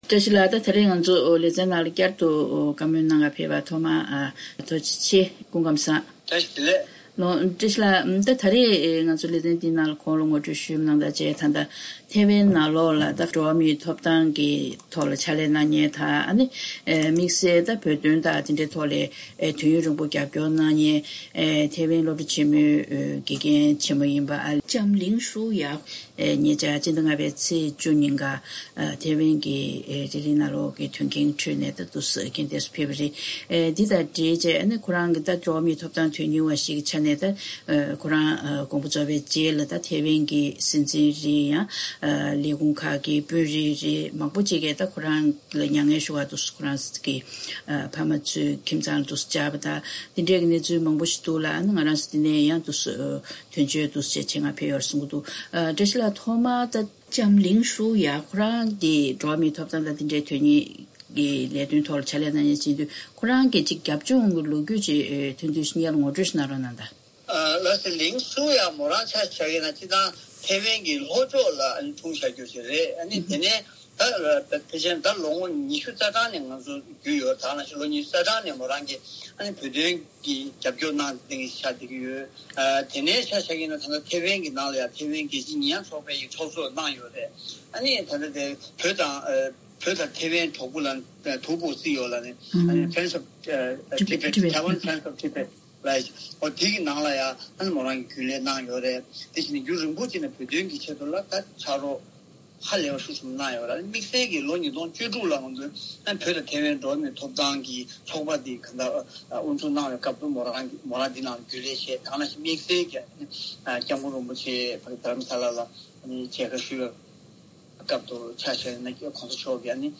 བཅའ་དྲིའི་ལེ་ཚན་ནང་།